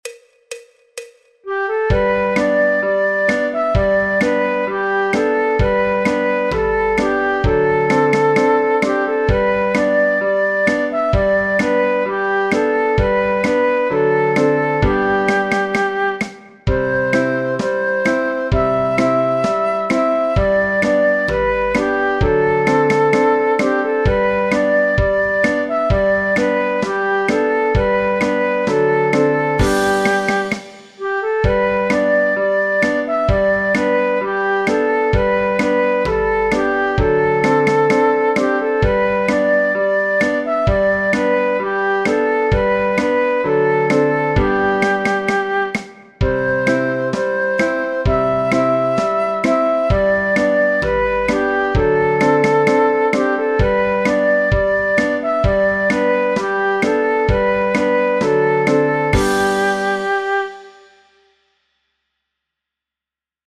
El MIDI tiene la base instrumental de acompañamiento.
Flauta Dulce, Flauta Travesera
Sol Mayor
Folk, Popular/Tradicional